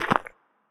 multiple step sounds
step-2.ogg